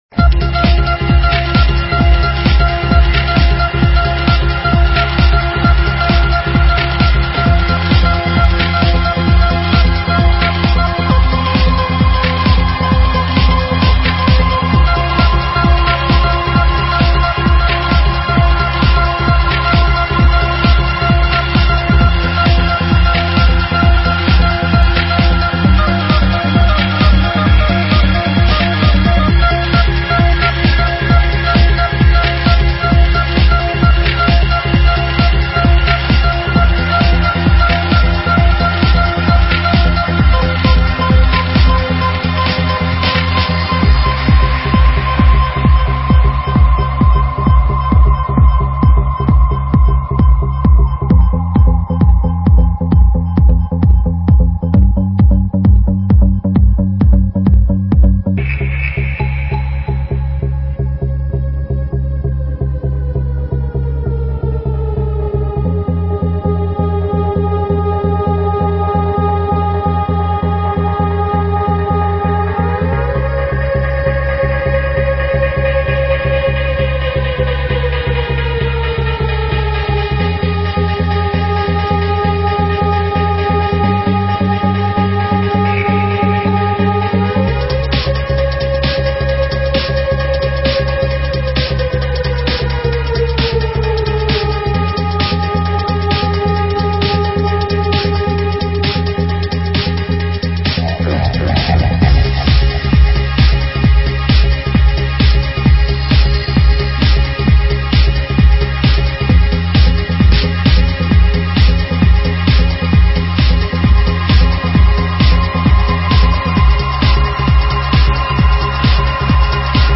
красивый мелодичный (trance